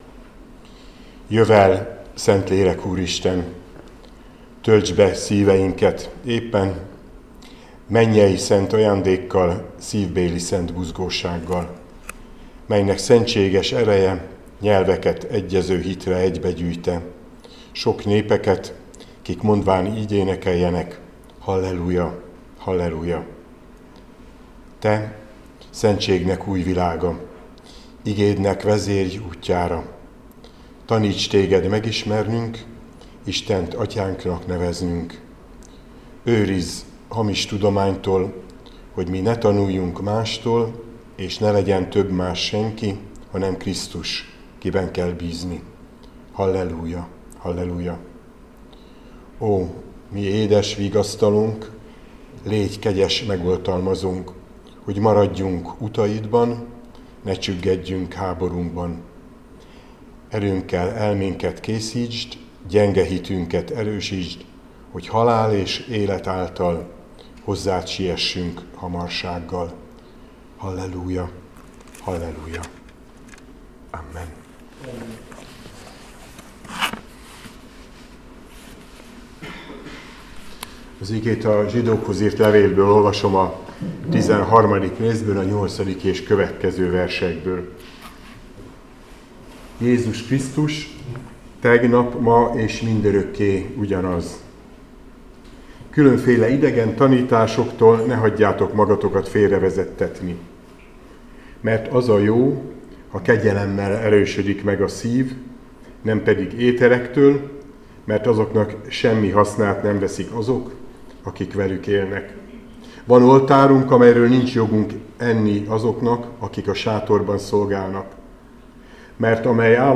Áhítat, 2026. március 31.
Zsid 13,8-15 Balog Zoltán püspök